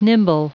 Prononciation du mot nimble en anglais (fichier audio)
Prononciation du mot : nimble